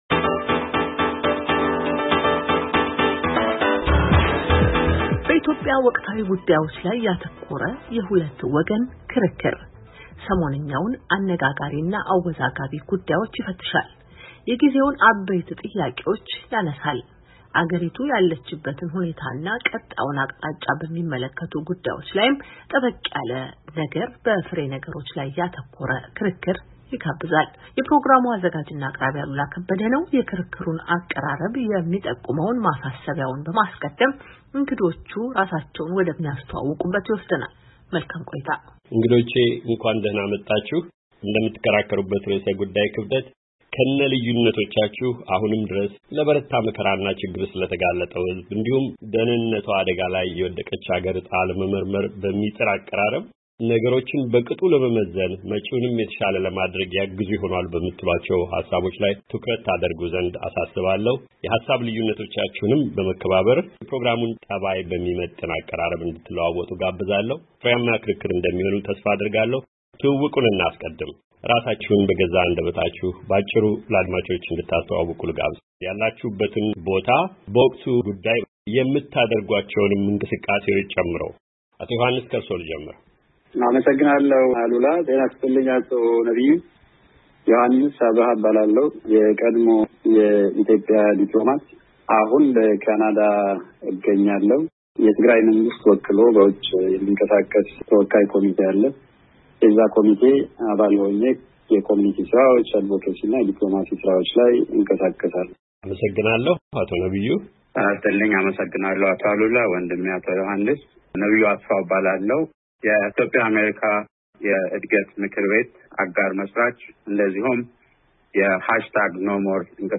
በሰሜን ኢትዮጵያው ቀውስ የሆነው፤ አሁን ያለው እና ቀጣዩ መንገድ! (ሙሉውን ክርክር ከዚህ ያድምጡ)
ሁለቱ ተከራካሪዎች “የኢትዮጵያ ጉዳይ” በሚል በተሰናዳው ክርክር የሚሟገቱባቸውን ጭብጦች በቅርበት ለመፈተሽ የምንሞክርባቸው ምዕራፎች ናቸው።